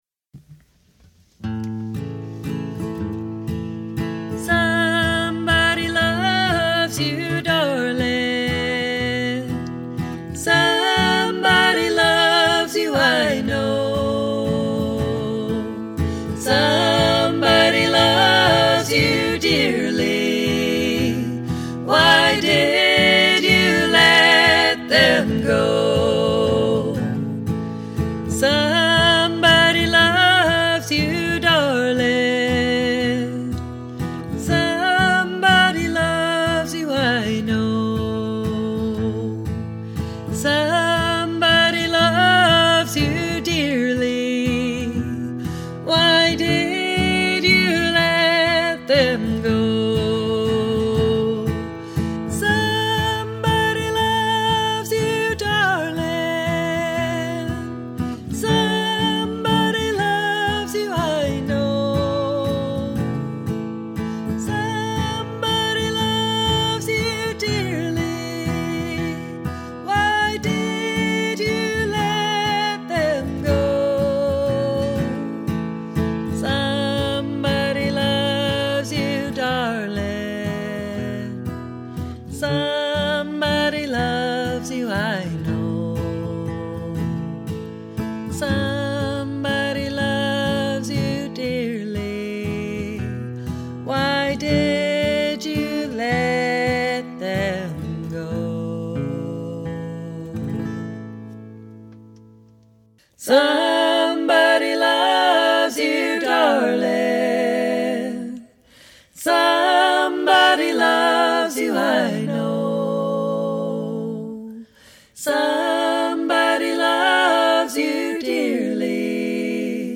Bluegrass Harmony Tracks